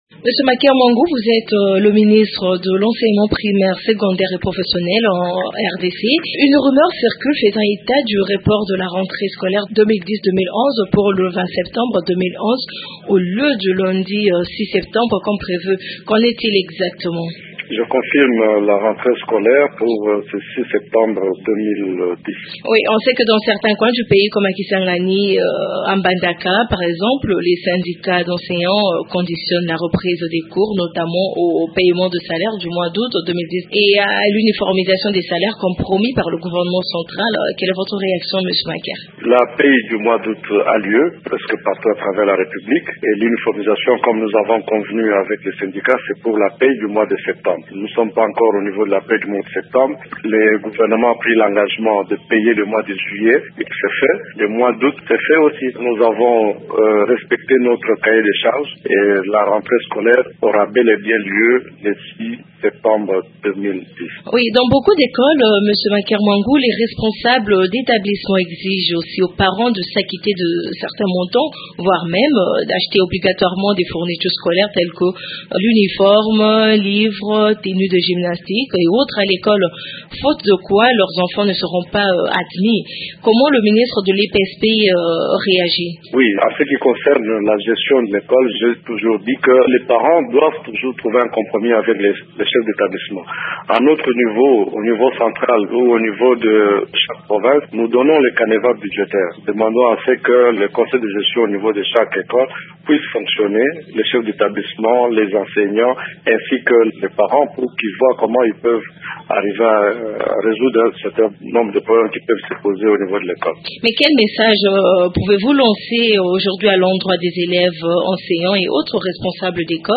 Dans une interview accordée à la Radio Okapi, le ministre répond aux questions relatives notamment à l’uniformisation des salaires tel que réclamé par les syndicats des enseignants, ainsi qu’à la gratuité de l’enseignement élémentaire tel que décrété par le chef de l’état.